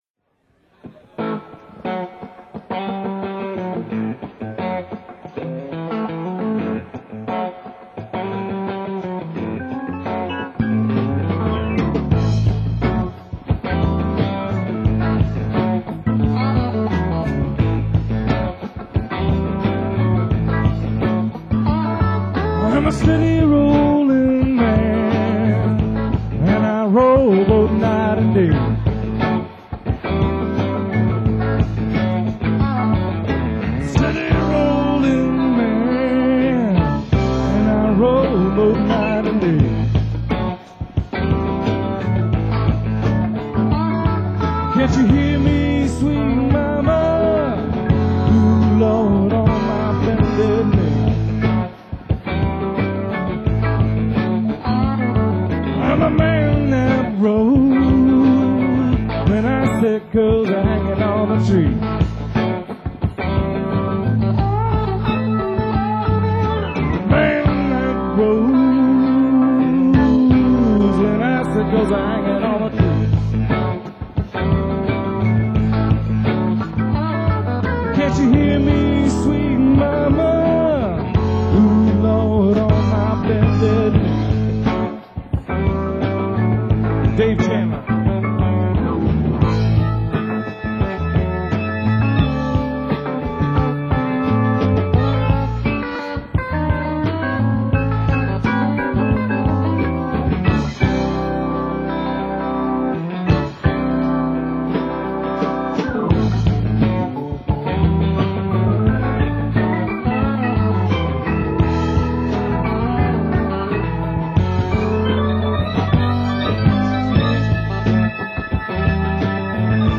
using a line level direct from the mixing consule.
keyboards & vocals
drums
bass & vocals
guitar & vocals